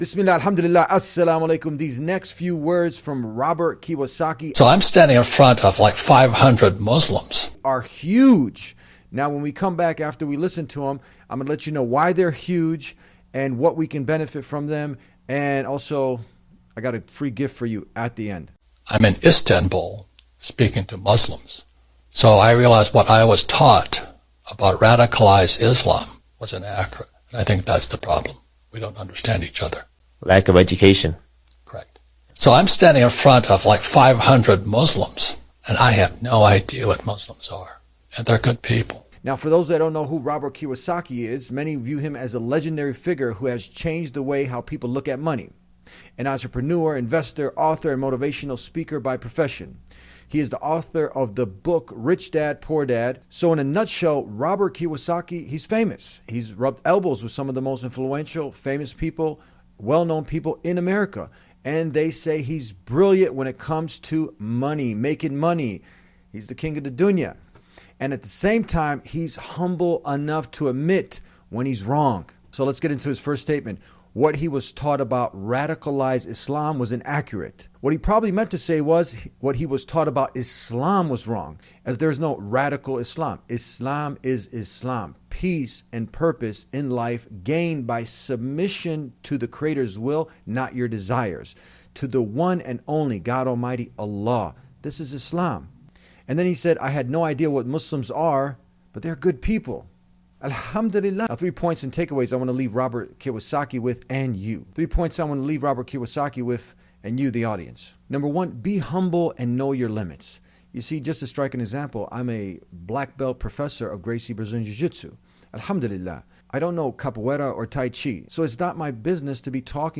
When Robert Kiyosaki, the bestselling author of Rich Dad Poor Dad and one of the most recognized voices in personal finance, stood before 500 Muslims in Istanbul, Turkey, something shifted. The man who has taught millions how to build wealth openly admitted that everything he had been taught about Islam was inaccurate.